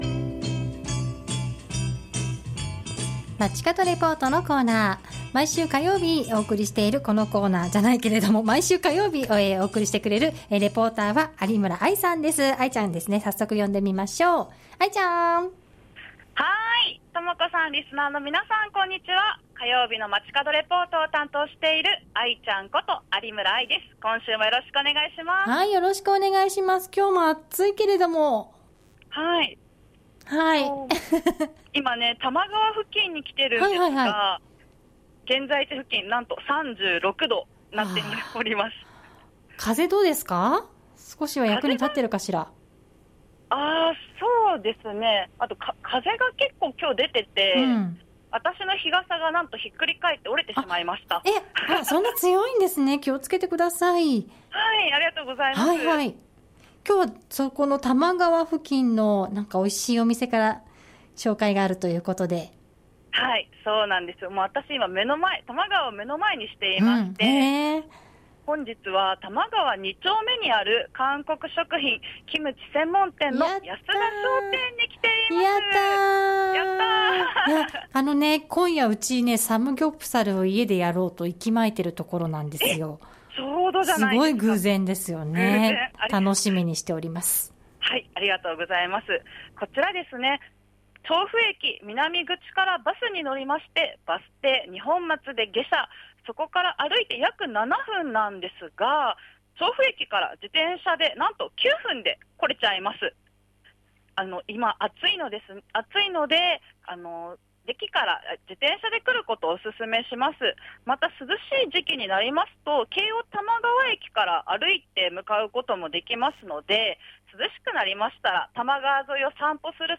本日は多摩川沿いにある韓国商品・キムチ専門店「安田商店」からお届けしました。